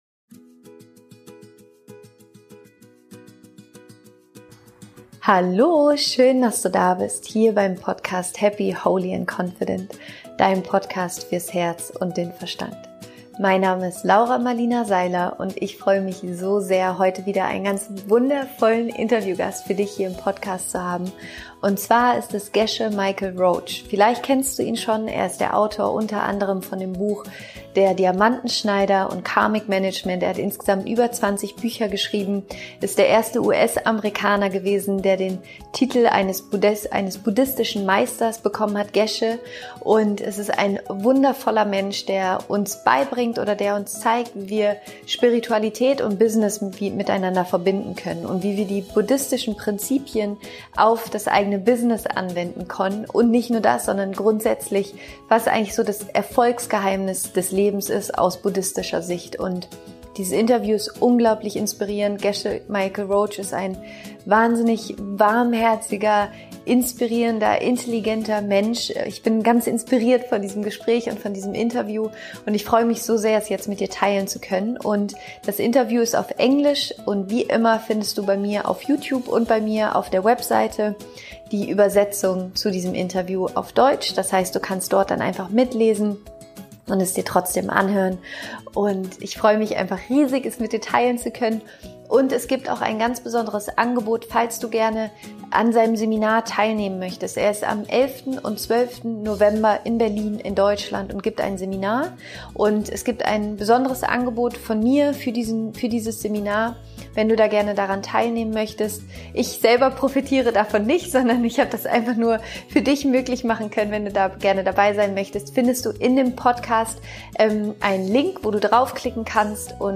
** Das Interview ist auf Englisch.